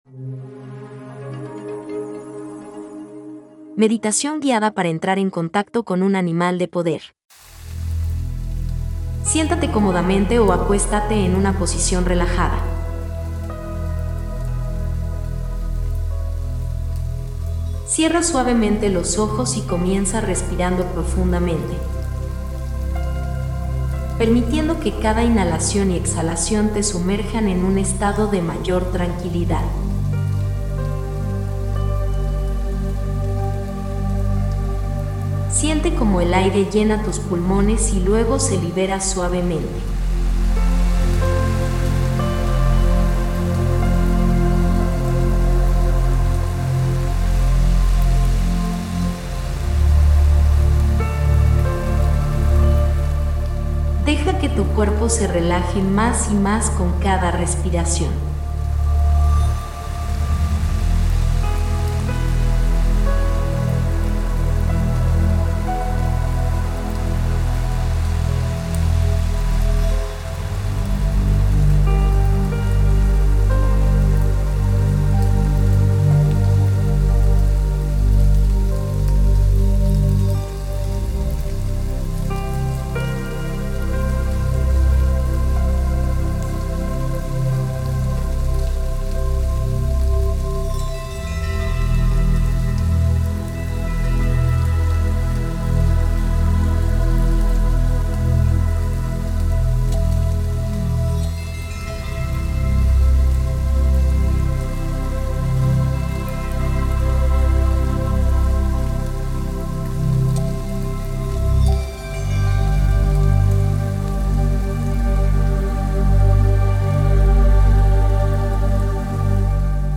Elige si prefieres escuchar el audio de la meditación o ver el video